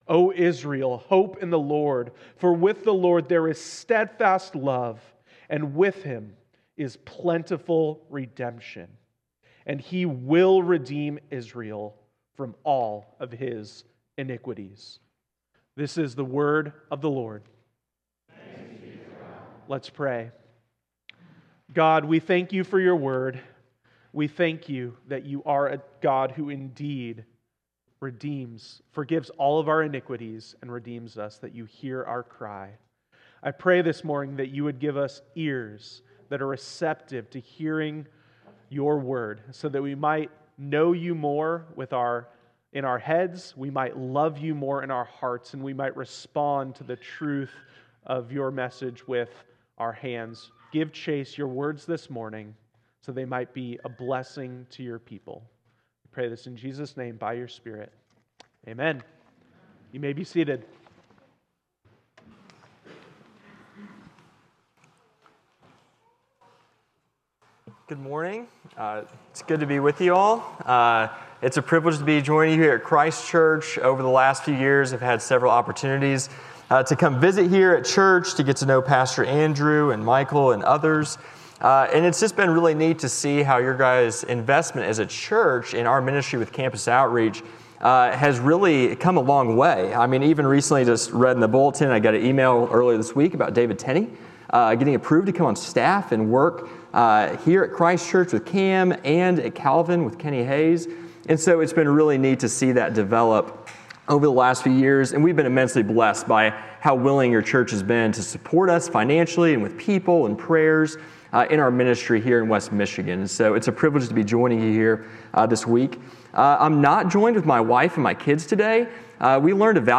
7.13.25 Sermon.m4a